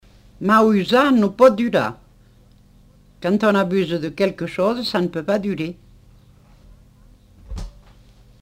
Aire culturelle : Comminges
Lieu : Montauban-de-Luchon
Genre : forme brève
Type de voix : voix de femme
Production du son : récité
Classification : proverbe-dicton